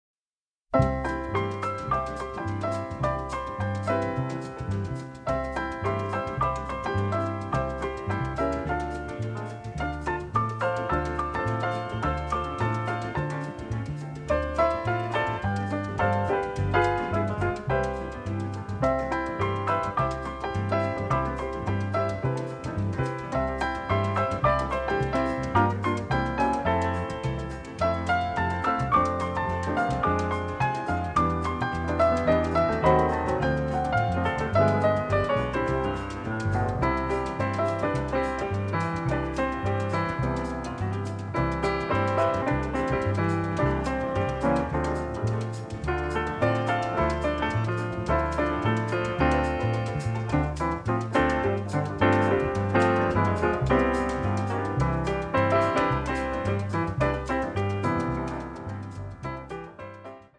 a colorful, bittersweet score
The score also features a bossa, a waltz and a tango.